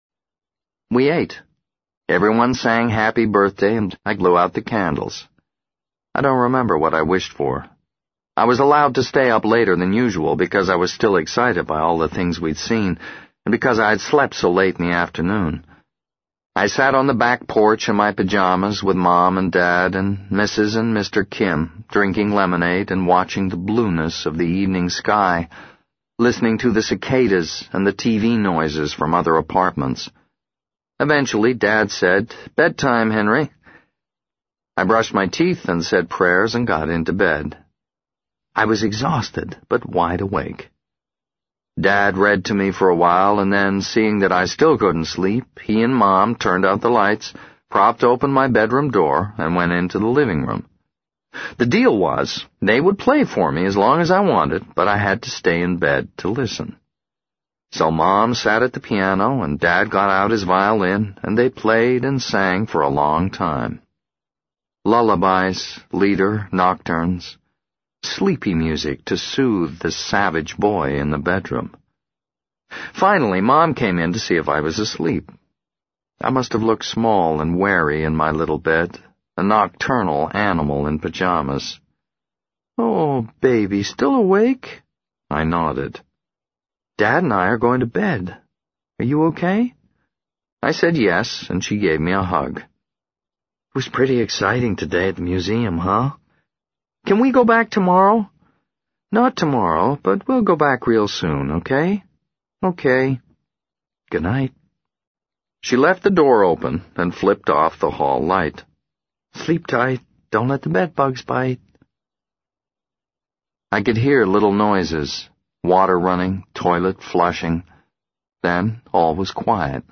在线英语听力室【时间旅行者的妻子】23的听力文件下载,时间旅行者的妻子—双语有声读物—英语听力—听力教程—在线英语听力室